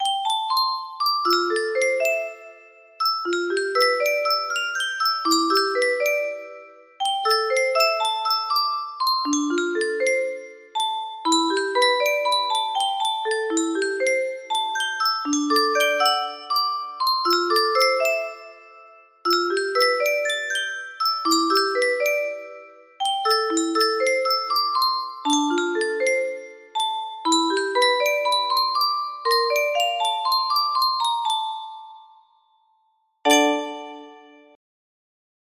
(F scale)